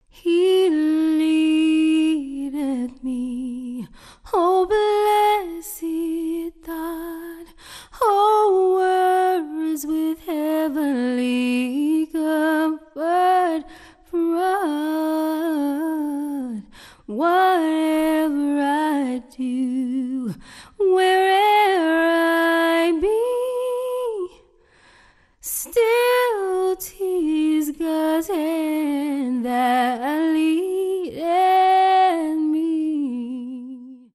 Your source for the best in A'cappella Christian Vusic ®
a cappella gospel songstress